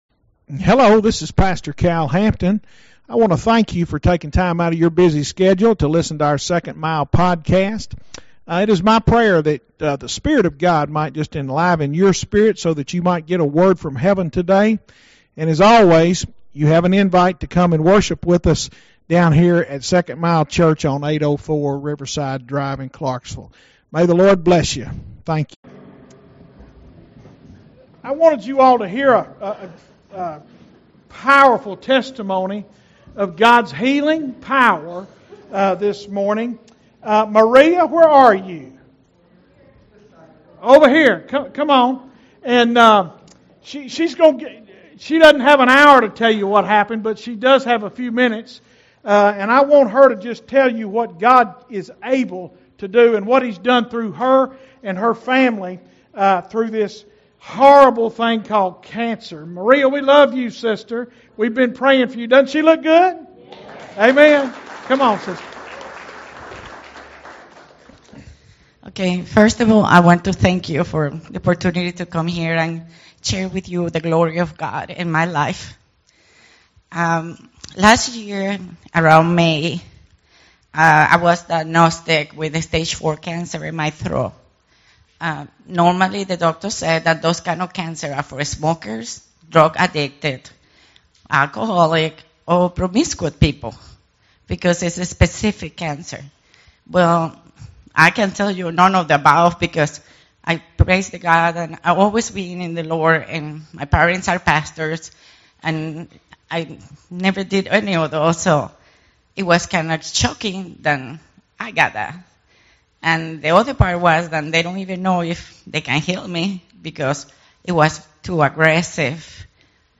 SERMONS - 2nd Mile Church
series: Sunday Morning Worship Please Note this Pod Cast contains